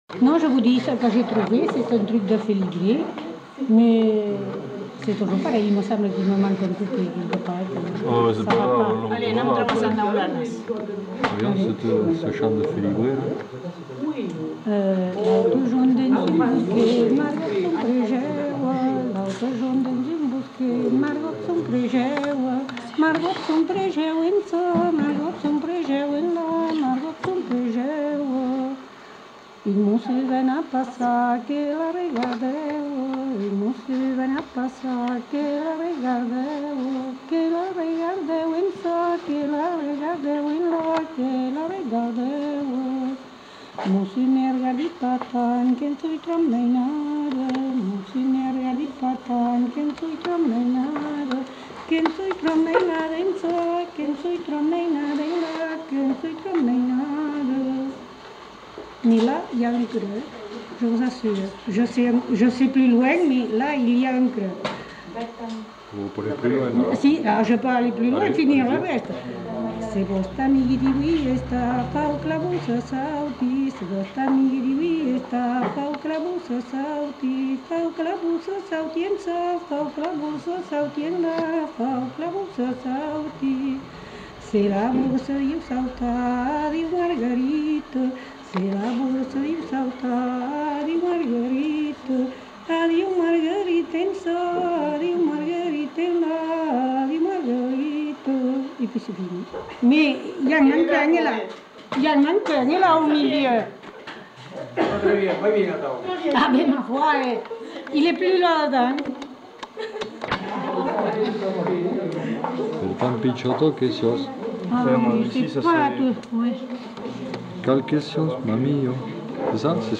Lieu : Allons
Genre : chant
Effectif : 1
Type de voix : voix de femme
Production du son : chanté